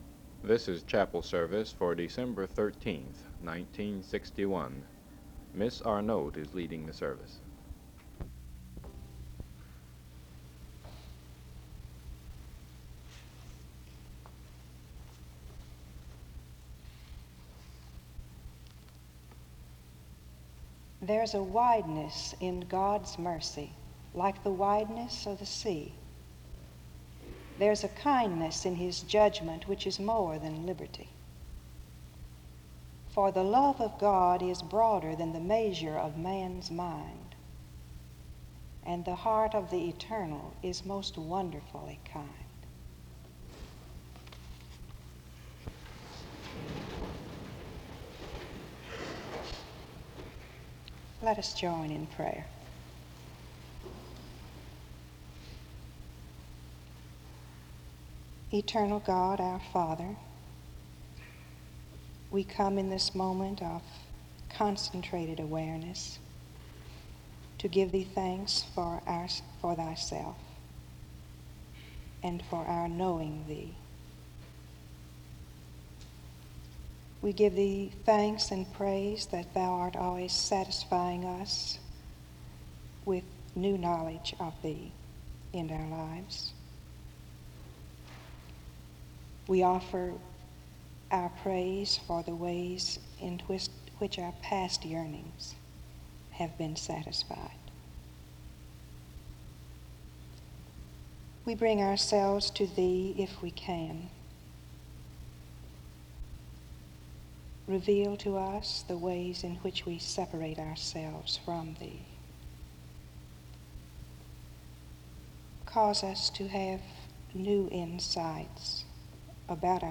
Download .mp3 Description The service begins with the reading of a poem about God (00:00-00:49) and prayer (00:50-05:10).
The audio is removed, but the opening tune of the hymn is audible (05:11-06:49). She begins by speaking of the tendency at certain times of the year to be caught up in good works and concern (06:50-08:09).
With her closing words, she shares that the good works of men act as buffers against all kinds of evils in the world (11:46-13:00). The service ends with music and singing (13:01-18:32).